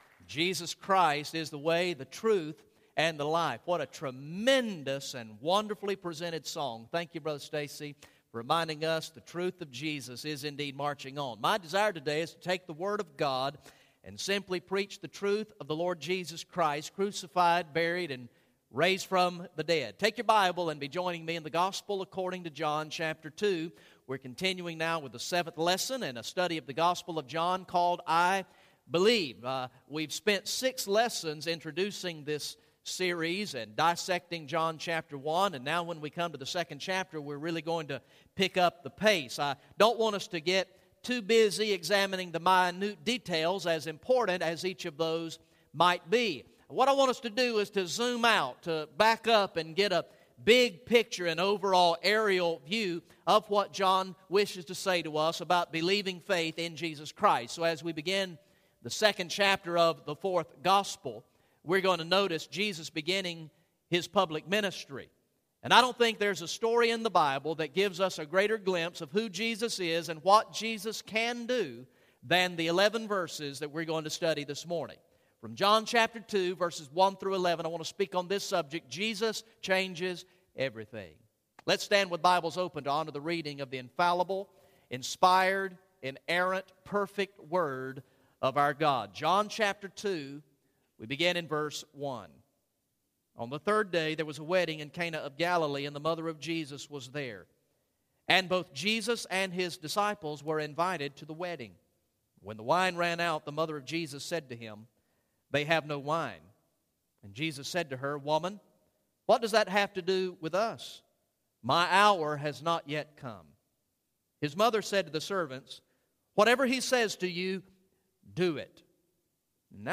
Message #7 from the sermon series through the gospel of John entitled "I Believe" Recorded in the morning worship service on Sunday, March 30, 2014